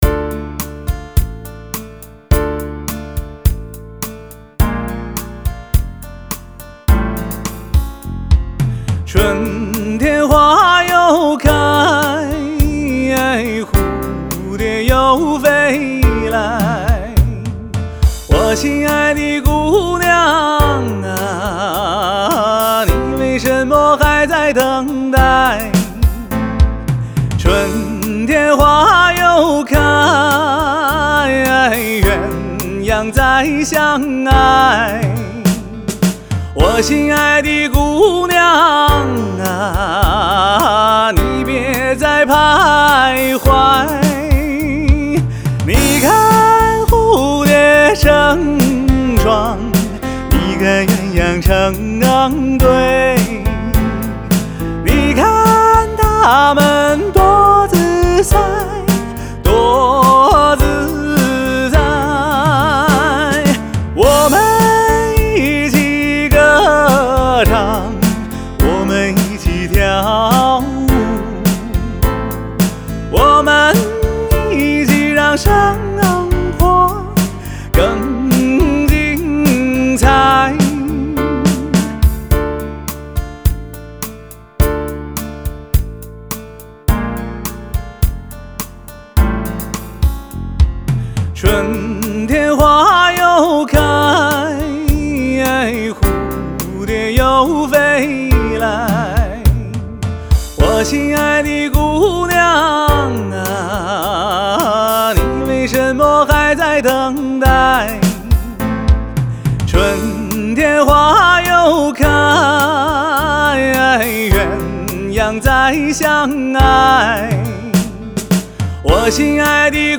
曲风：轻音乐